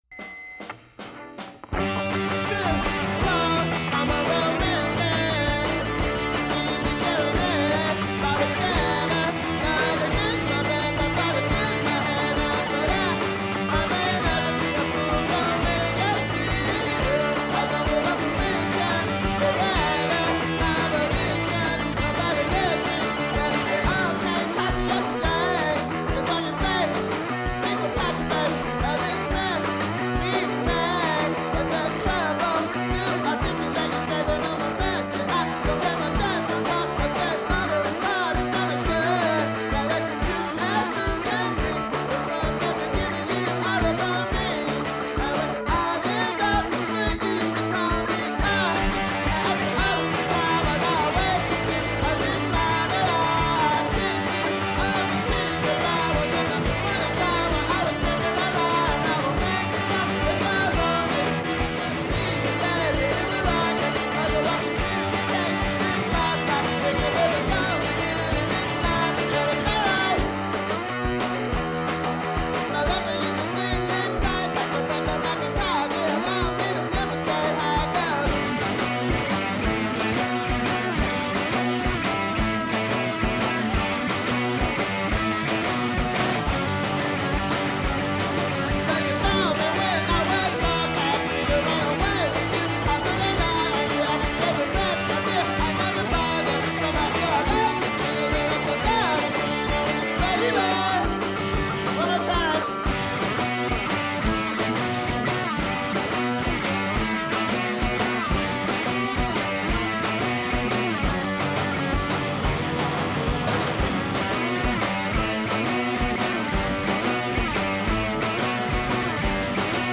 La scène garage pédé de la Bay Area